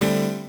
02_Crunchy.wav